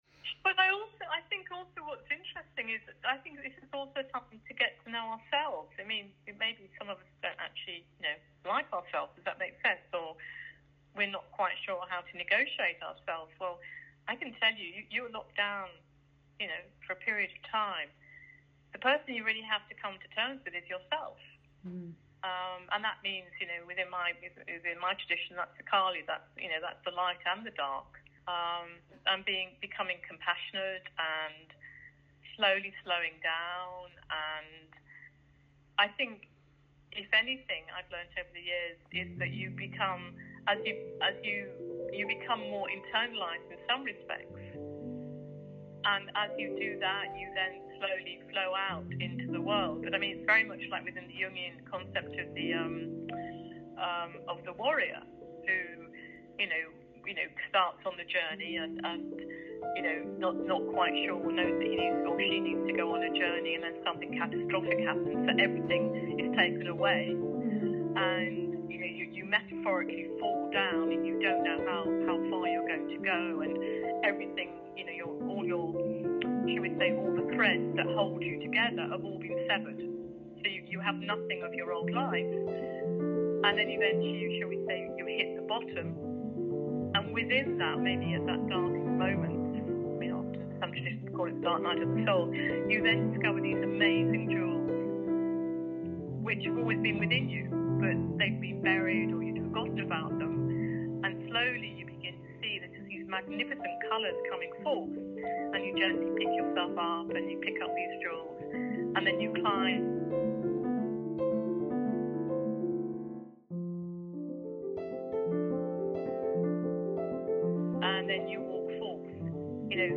The following is an interview